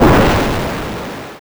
explosion_4.wav